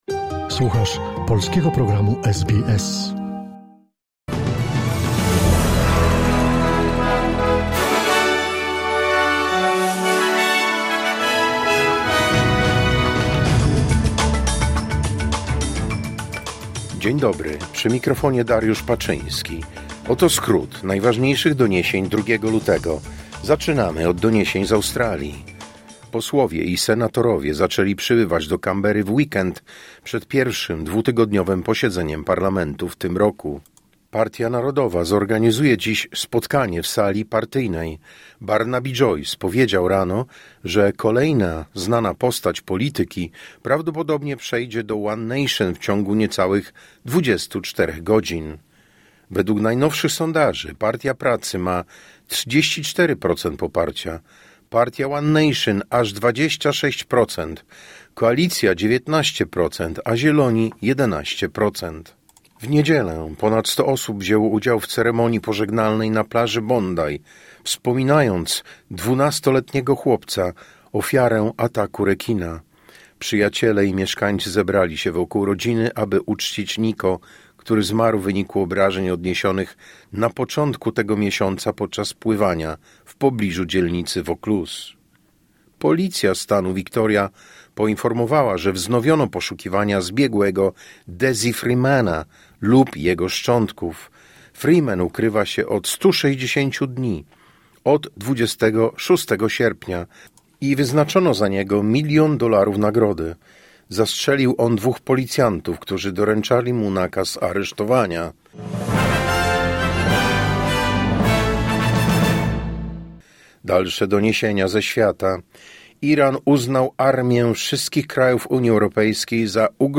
Wiadomości 2 Lutego SBS News Flash